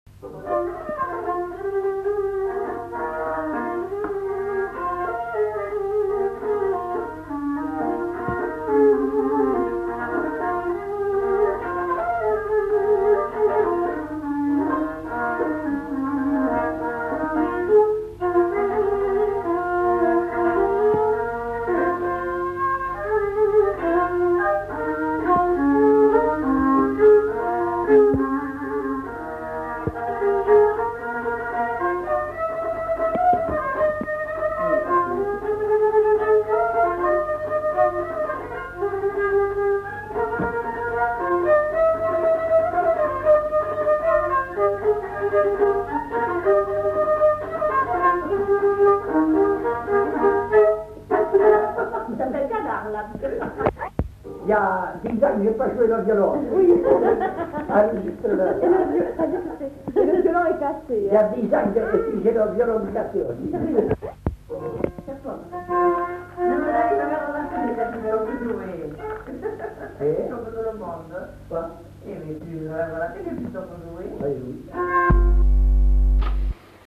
Aire culturelle : Marsan
Lieu : Haut-Mauco
Genre : morceau instrumental
Instrument de musique : violon
Danse : valse